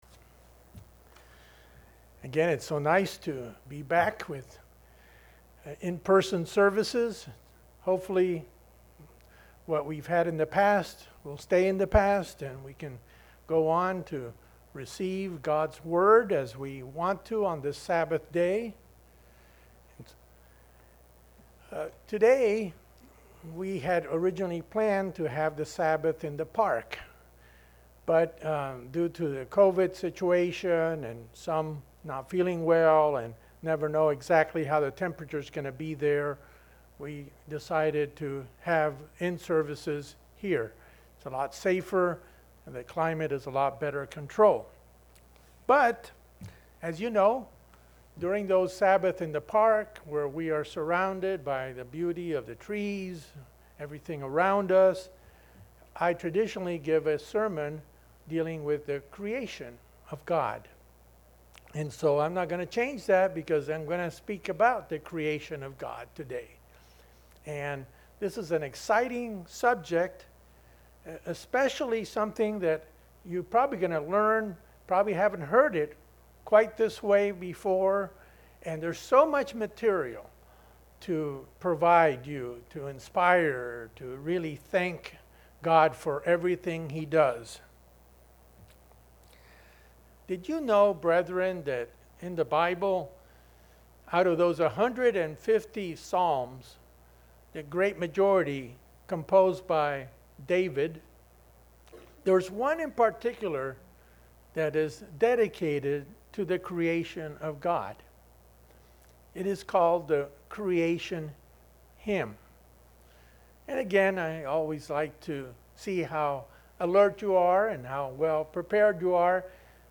In Psalm 104, David expresses how God sustains and provides for His Creation. Listen now to this message, the first of a 2-part series.